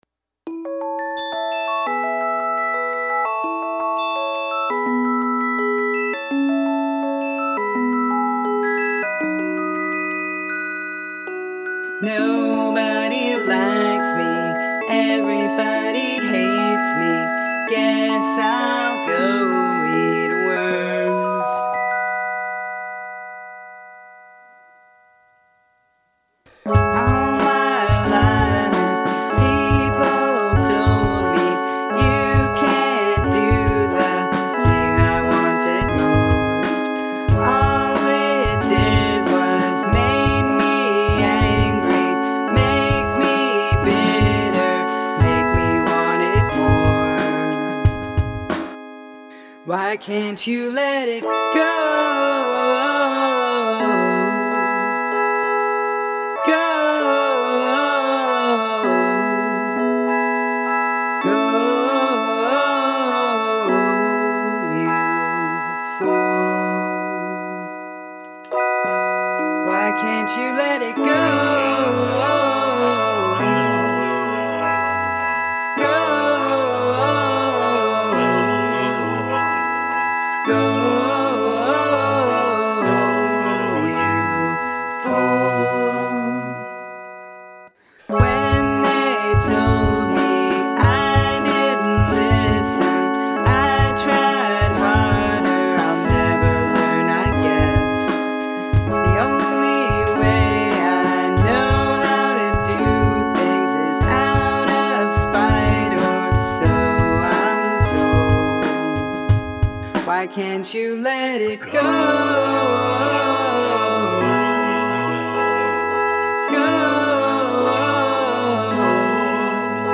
Must include a guest from outside the band singing a vocal harmony
drums are nice and punchy, but the Qchord (?) strums are frequently off the beat and it really takes me out of thge song.
I think actually though that tightening that stuff up might lead to a very listenable lofi indie tune.
I like the nursery rhyme feel to the intro before the drop into the darker verse.
However, there are some iffy pitch and timing issues in the vocals.